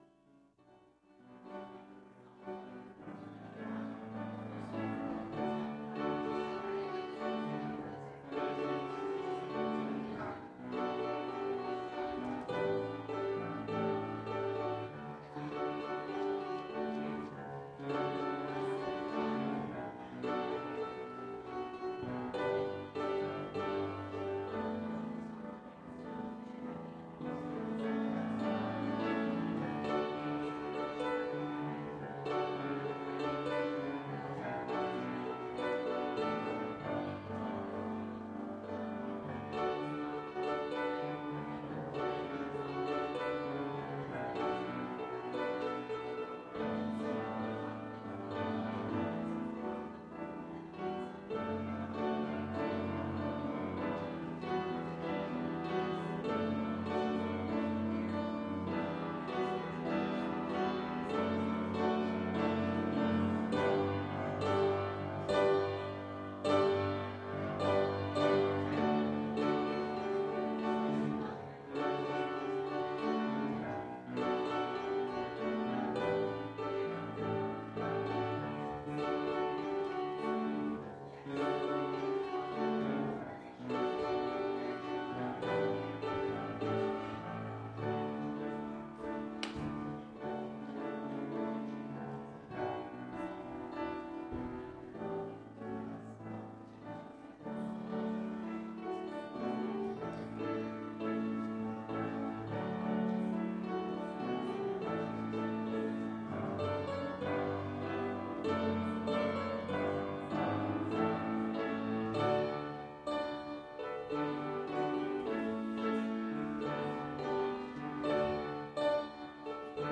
Calvin Christian Reformed Church Sermons
ORDER OF WORSHIP